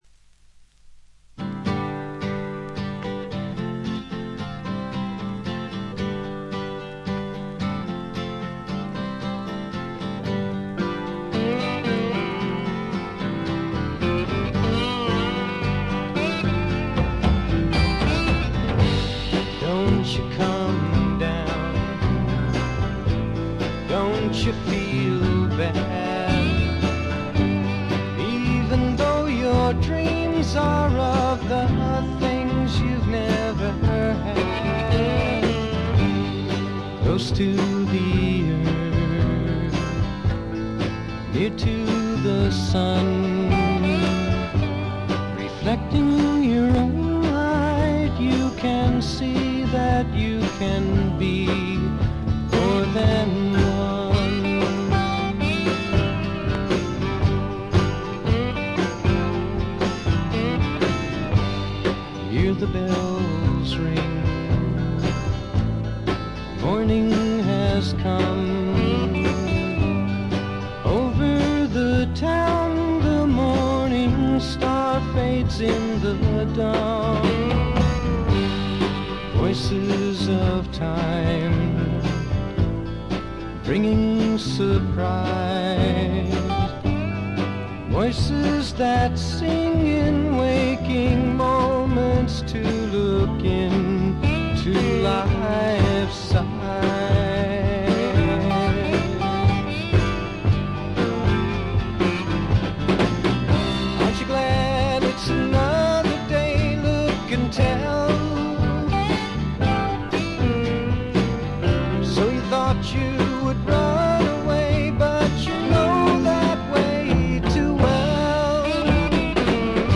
これ以外は軽微なバックグラウンドノイズにチリプチ少々。
試聴曲は現品からの取り込み音源です。
Recorded at The Village Recorder